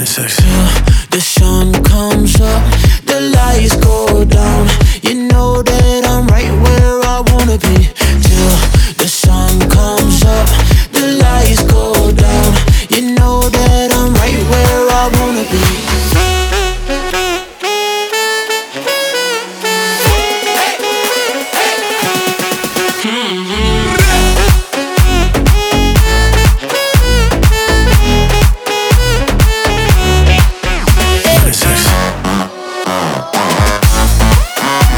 Жанр: Танцевальные / Поп